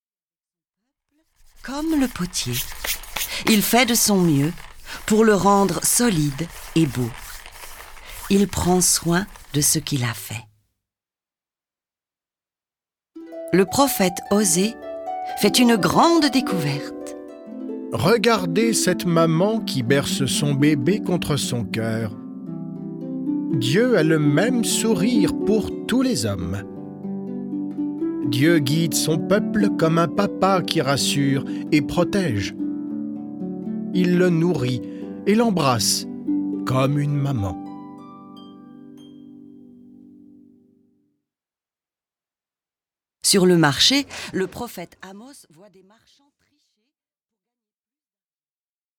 Racontée à plusieurs voix par des comédiens de talent, au son d'instruments qui rappellent le Moyen-Orient, cette Bible saura captiver petits et grands.